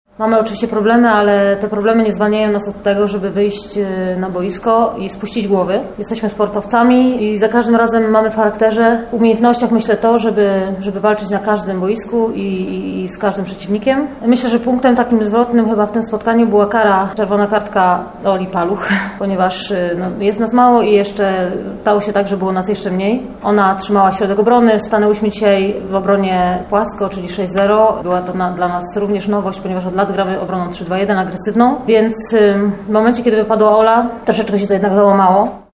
Oto co powiedziały bohaterki meczu podczas konferencji prasowej po jego zakończeniu: